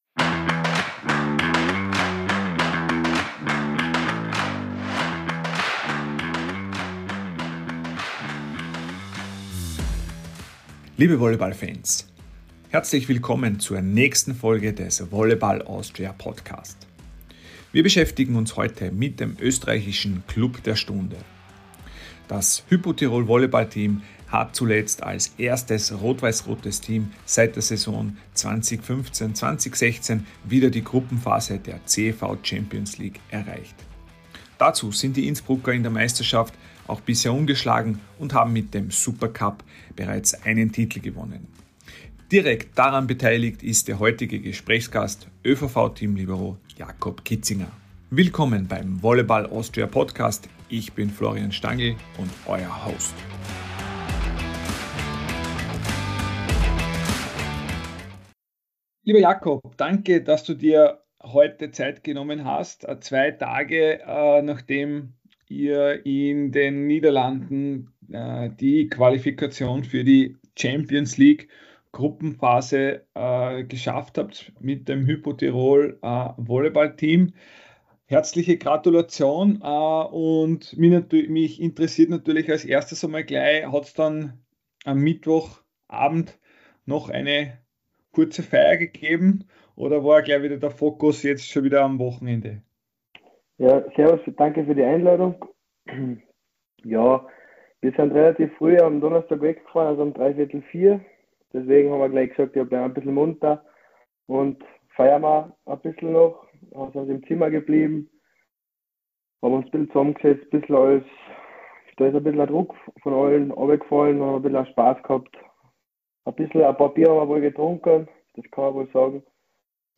im Gespräch ~ Volleyball Austria Podcast